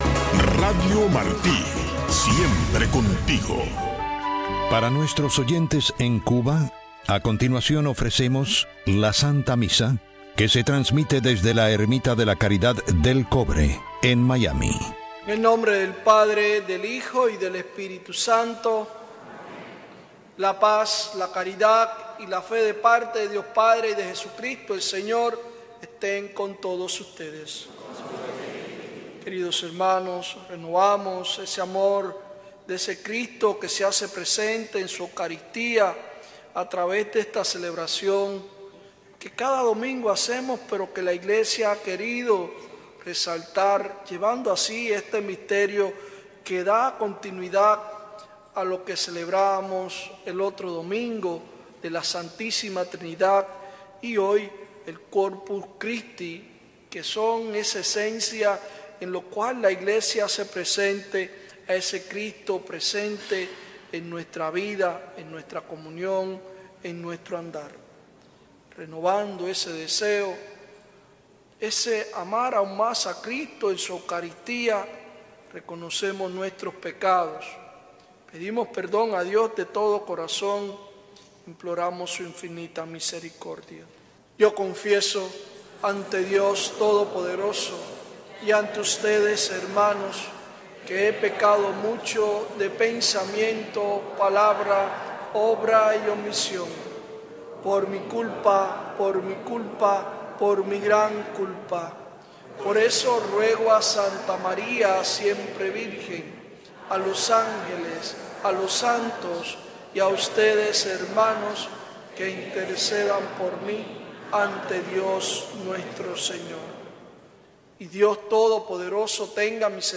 El gobierno cubano desató una oleada represiva tras las protestas del 11 de julio en Cuba. Madres de las víctimas y madres arrestadas en el contexto de las manifestaciones, dan testimonios de violaciones a los derechos humanos y judiciales en Cuba.